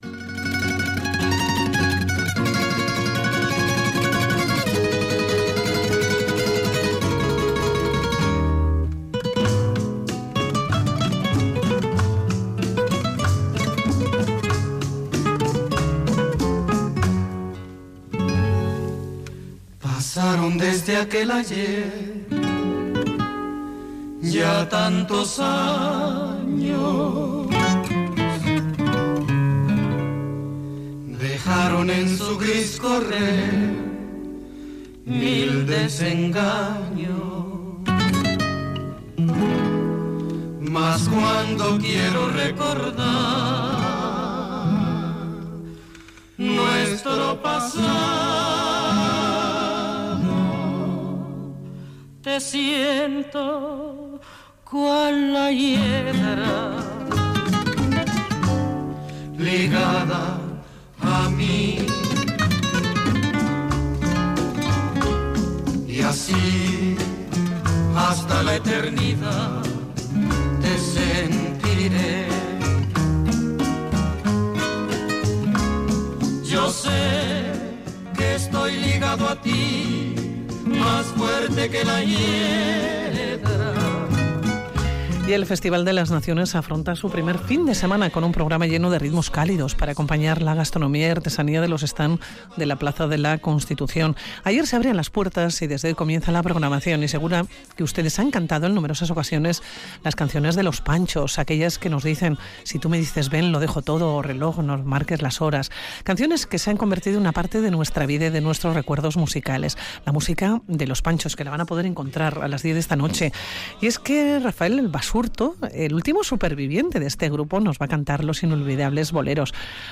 Audio: Radio Vitoria| Música en directo y muchas anécdotas con Rafael Basurto, el útlimo superviviente de Los Panchos.